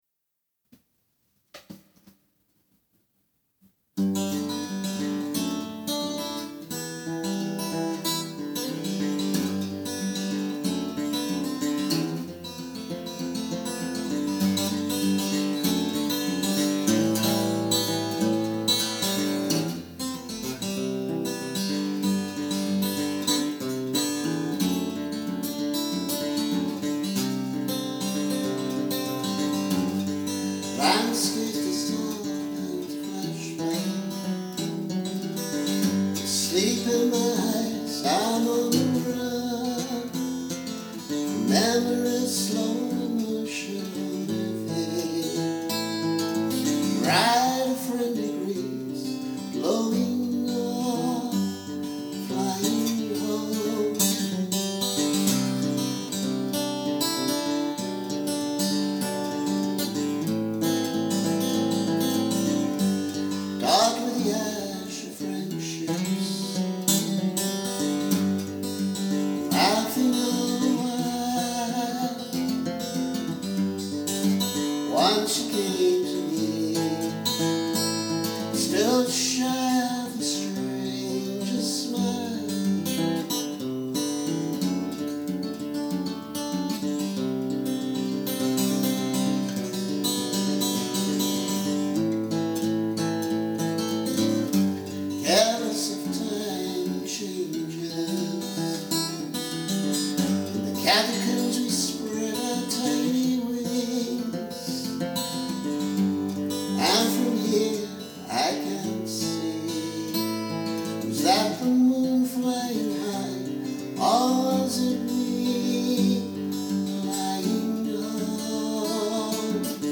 seesaw [very rough demo]